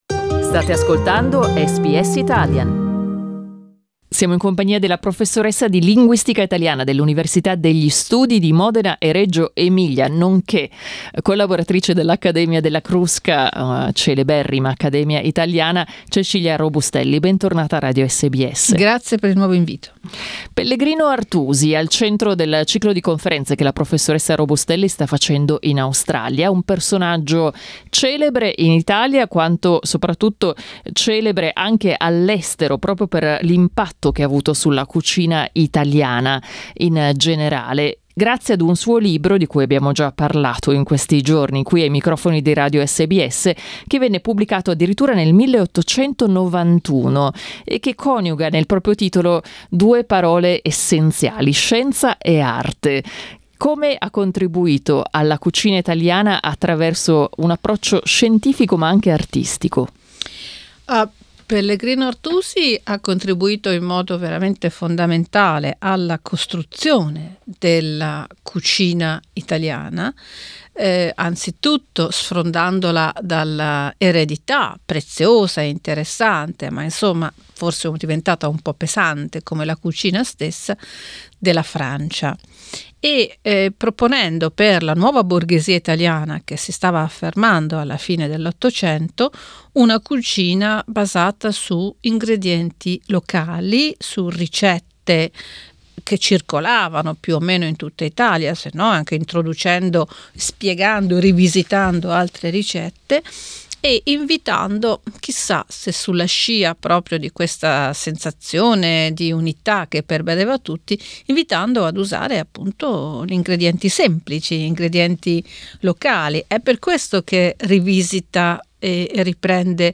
In questa intervista